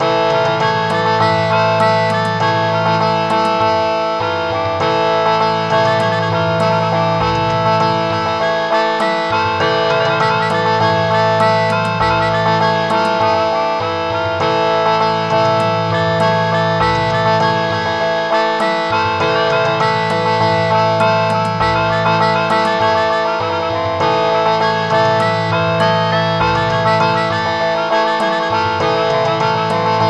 Pulled from game files by uploader
Converted from .mid to .ogg